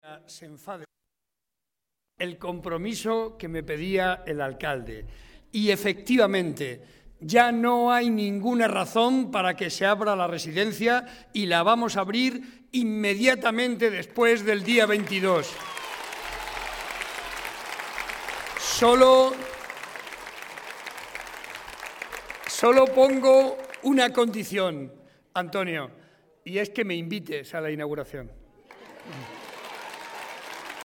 El presidente de Castilla-La Mancha y candidato a la reelección, José María Barreda, quiso comenzar su intervención en Villafranca de los Caballeros (Toledo) comprometiéndose a abrir la residencia para mayores de la localidad «en cuanto pase el día 22». Los asistentes recibieron con aplausos la noticia que agradecieron mostrándole al presidente su cariño y su apoyo.